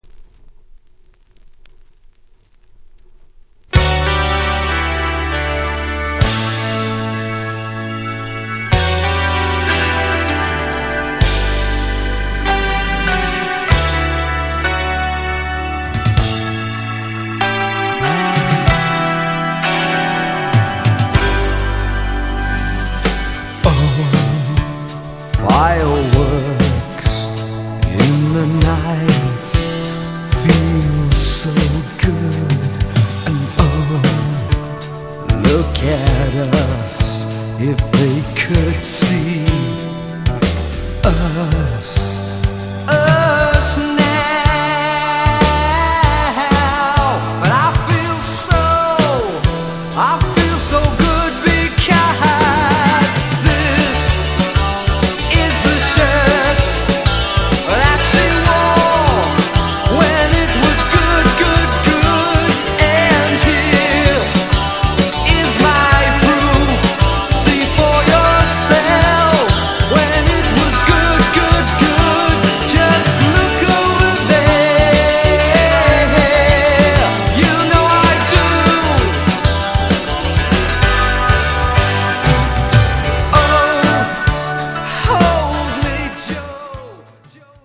from Liverpool リバプールのデュオ・ネオアコ。